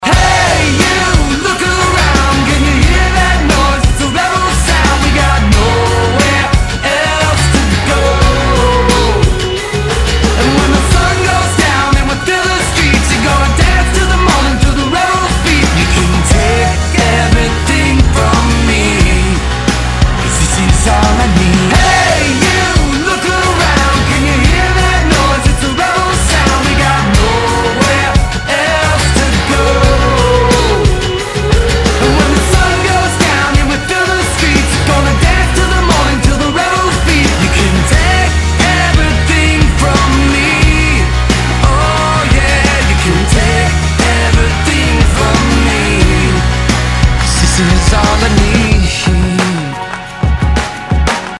Танцевальные